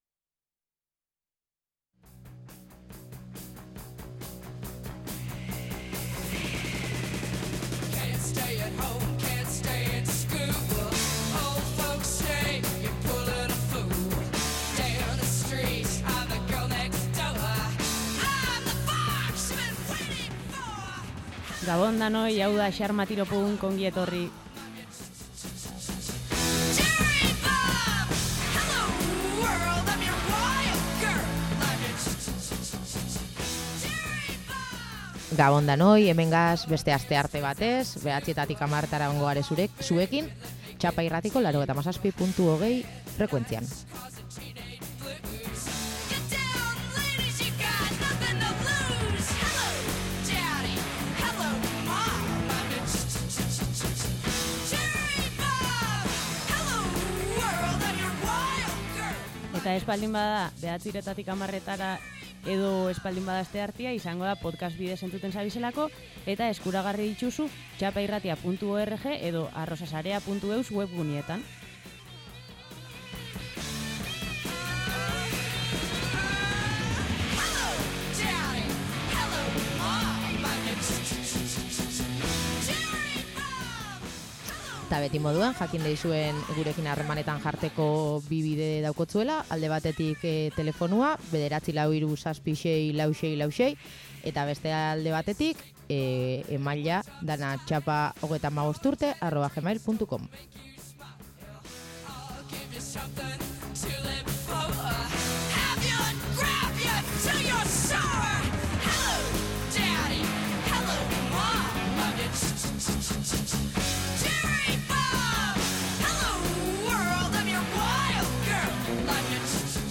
Jada gustua hartzen hasi gara txapa irratian grabatzeari. Hementxe entzungai duzue bertatik emititutako bigarren saioa.